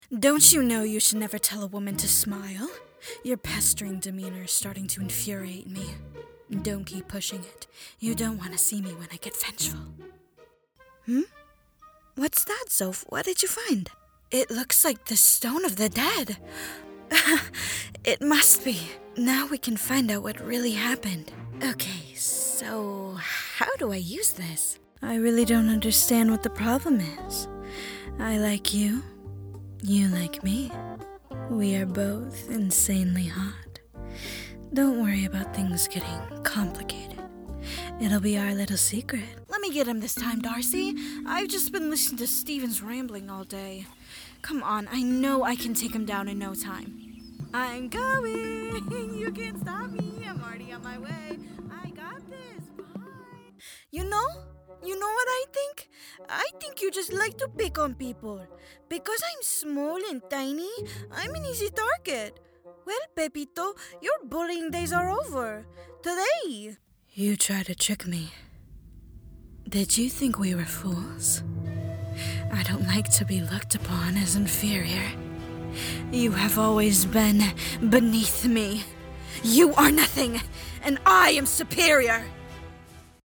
Animation Demo
Animation Demo.mp3